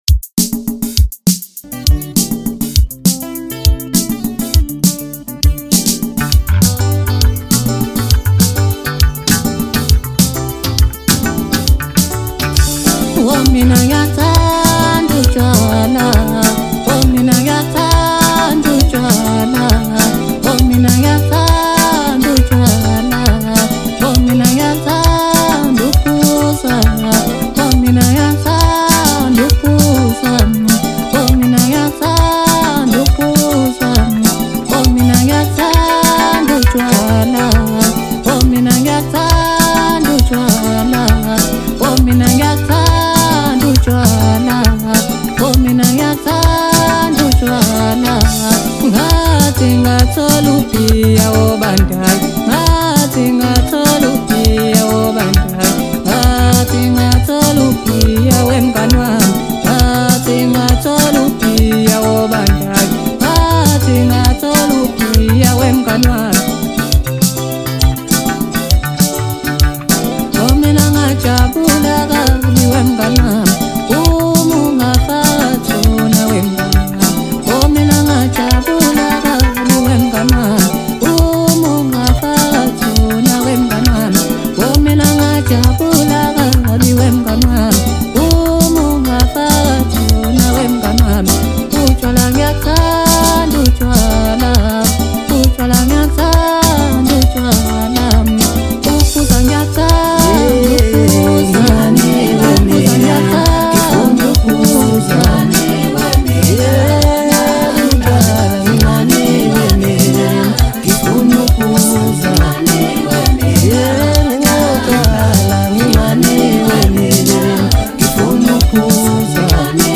Home » Deep House » Hip Hop » Latest Mix » Maskandi